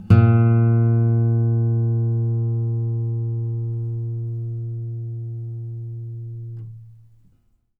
bass-07.wav